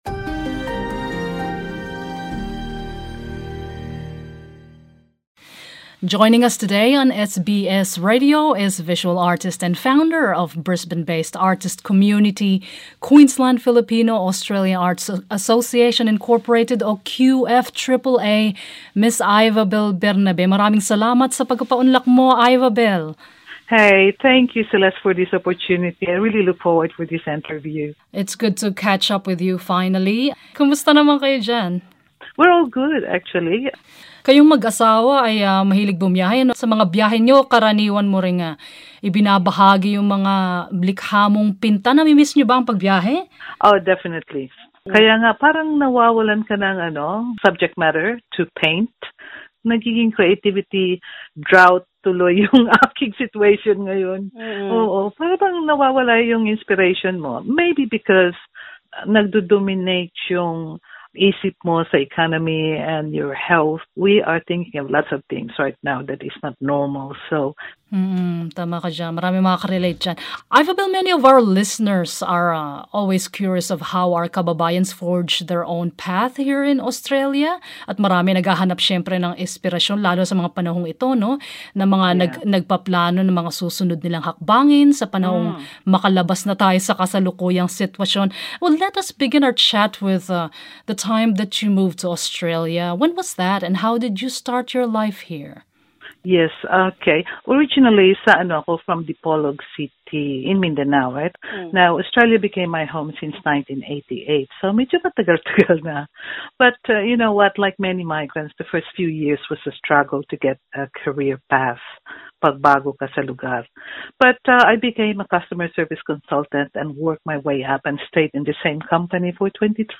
brisbane_artist_intvw.mp3